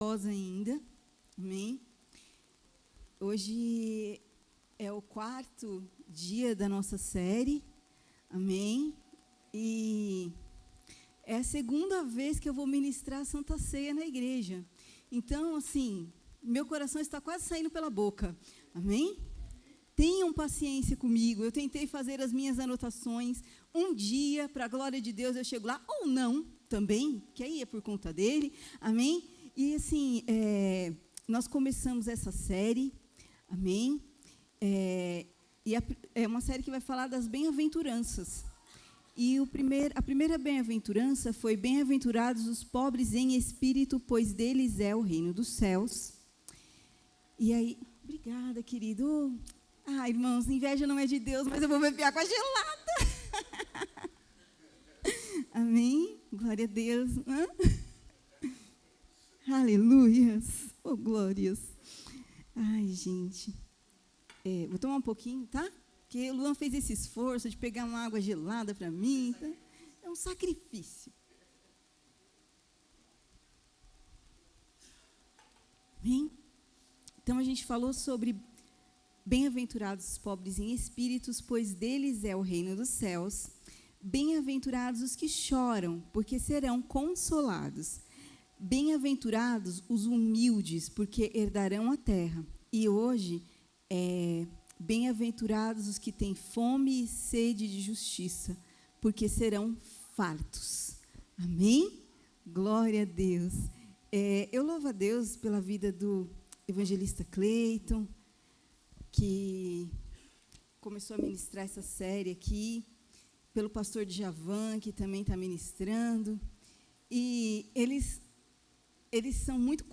sermao-mateus-5-6.mp3